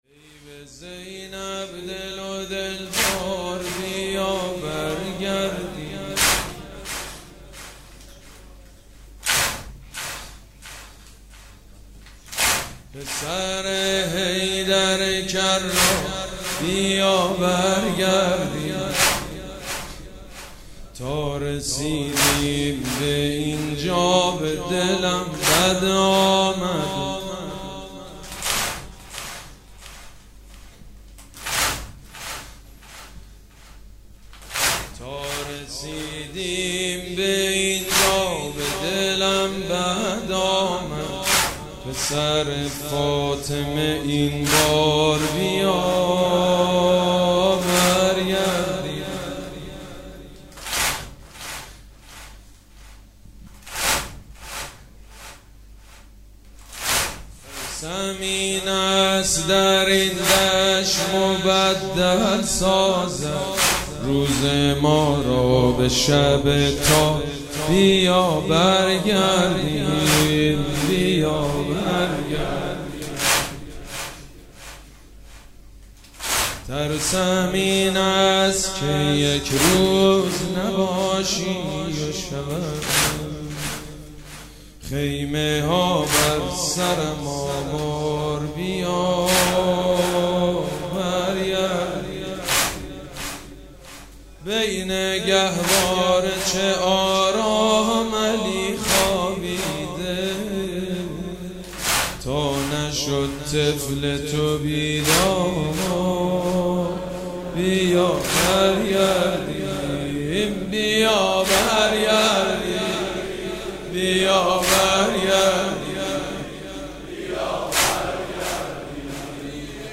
مراسم عزاداری شب دوم محرم الحرام ۱۴۴۷
مداح
حاج سید مجید بنی فاطمه